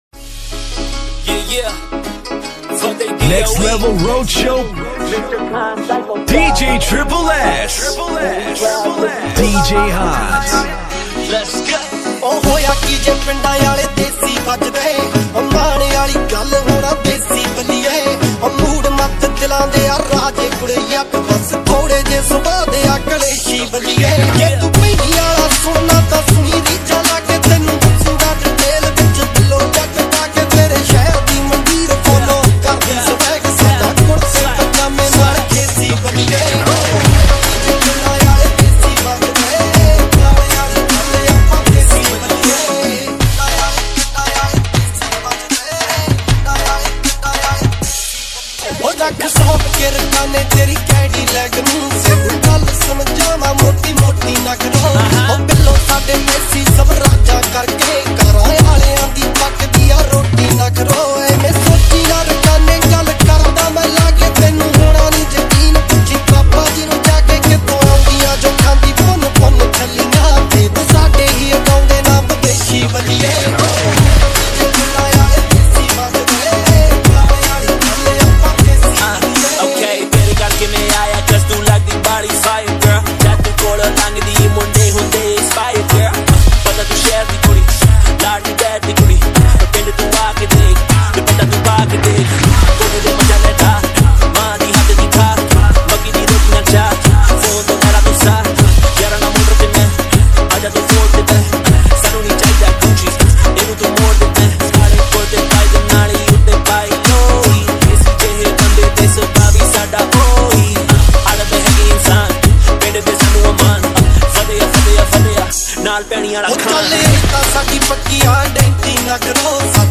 Haryanvi Songs 2021
DJ Remix Songs